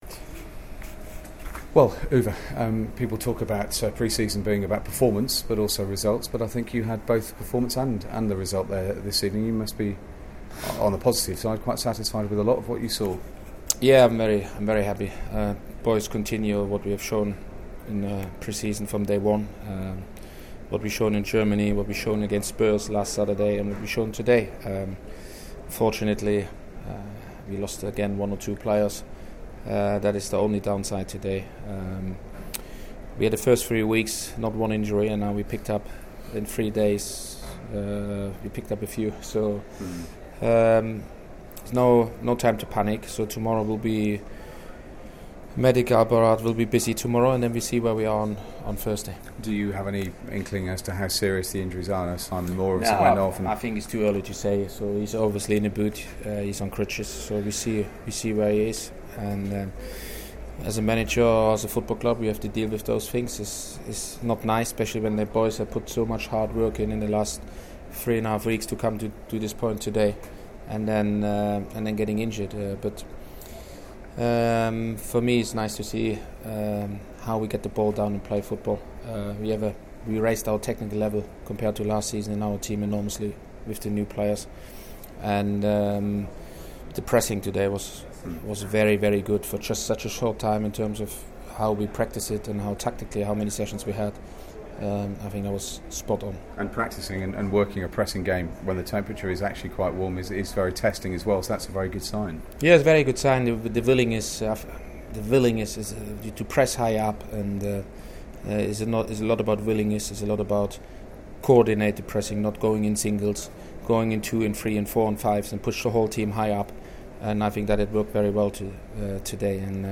spoke to Uwe Rosler following the 3-0 friendly win for Brentford over Millwall. About injury to Simon Moore, and prospect of new signings